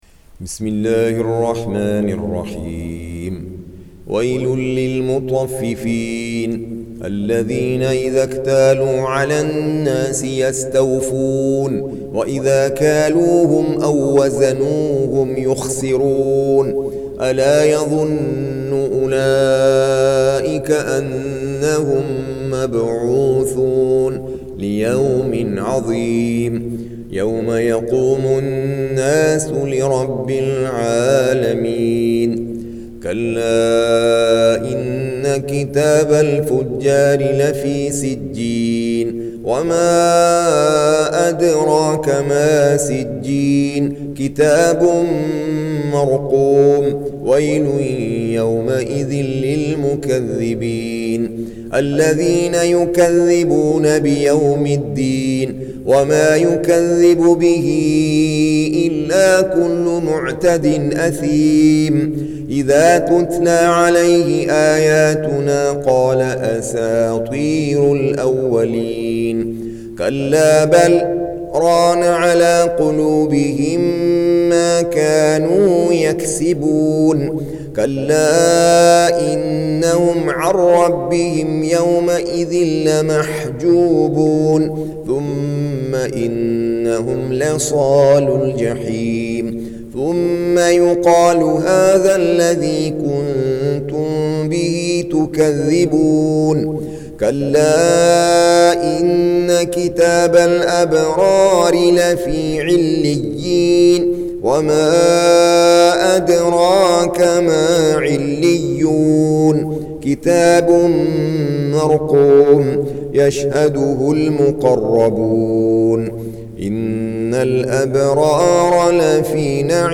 Surah Repeating تكرار السورة Download Surah حمّل السورة Reciting Murattalah Audio for 83. Surah Al-Mutaffif�n سورة المطفّفين N.B *Surah Includes Al-Basmalah Reciters Sequents تتابع التلاوات Reciters Repeats تكرار التلاوات